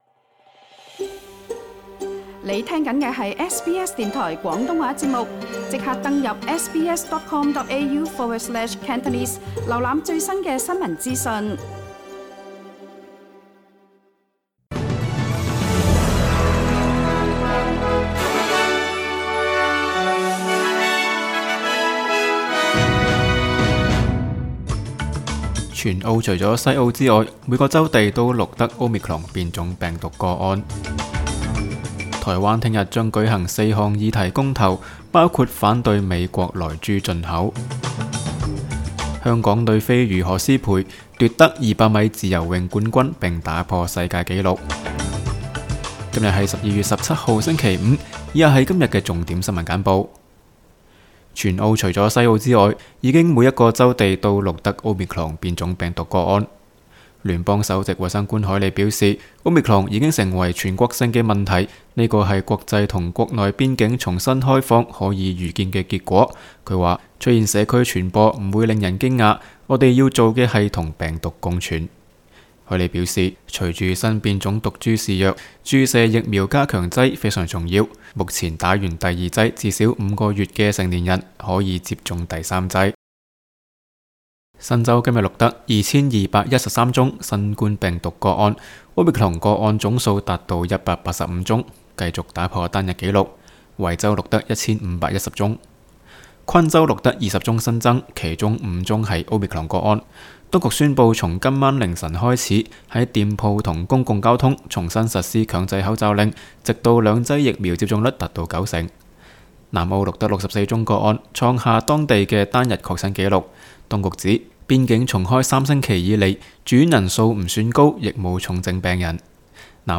SBS 新闻简报（12月17日）